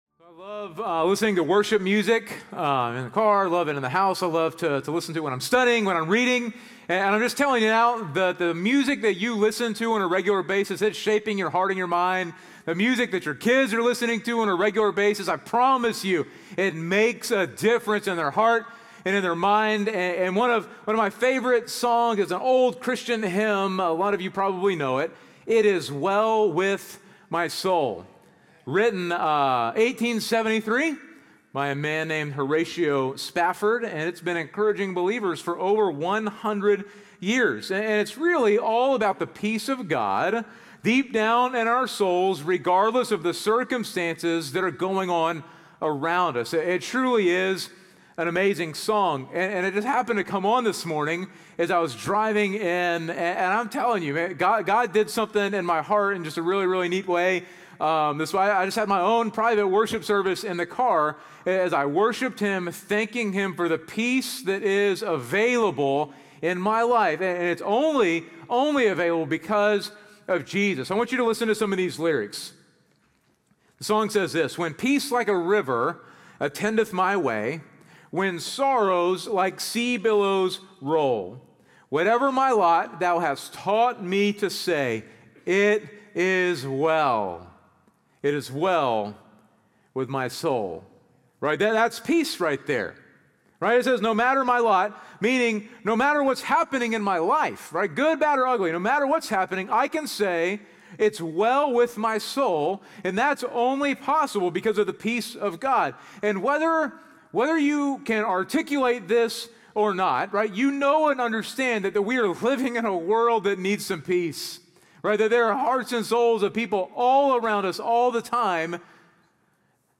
North Klein Sermons – Media Player